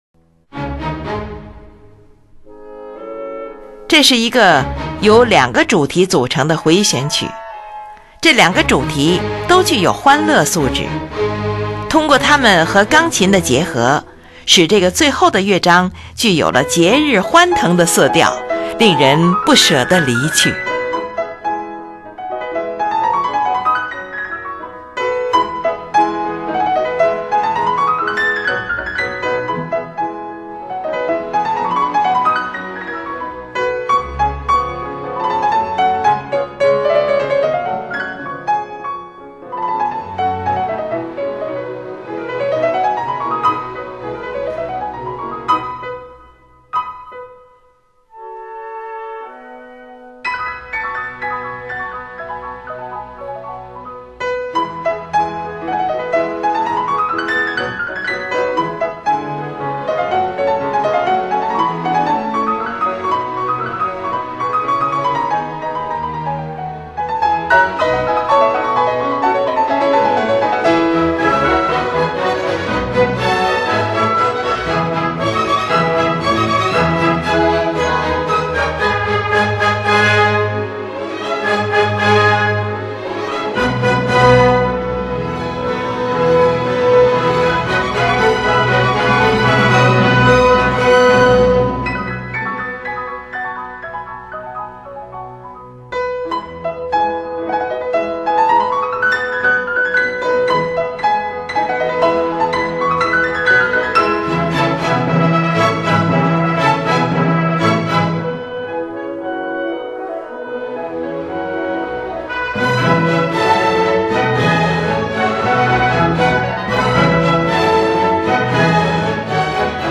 Rondo Vivace
试听：第三乐章，回旋曲，甚快板，E大调 64K/Wma